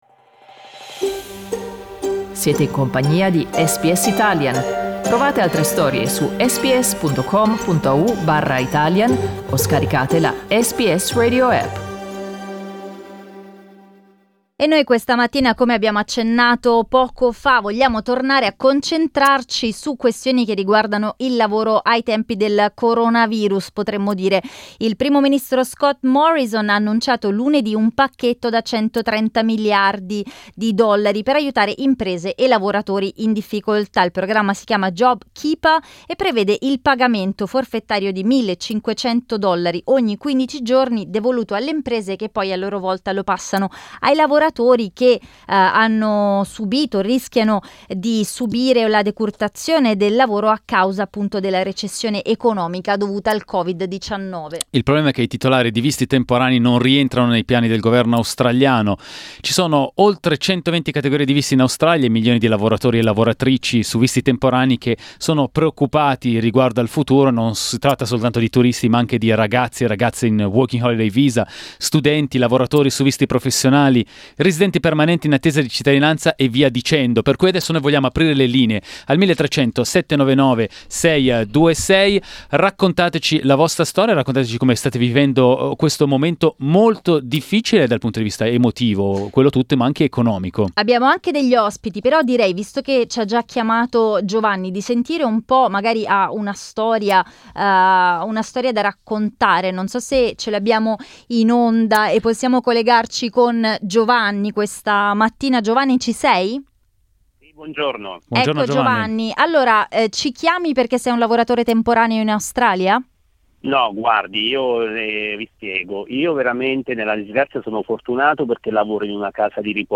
Temporary visa holders in Australia are worried about their future. We dedicated our talkback to Italians who are currently Down Under with these visas.